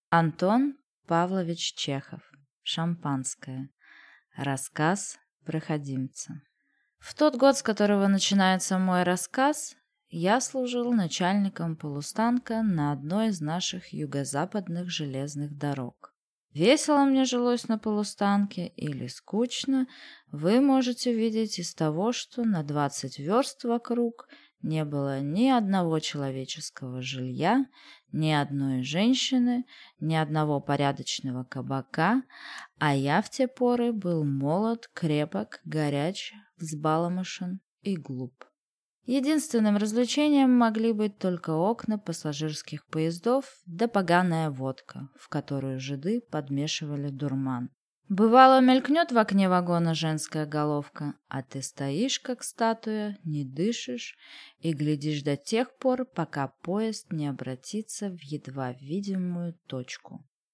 Аудиокнига Шампанское | Библиотека аудиокниг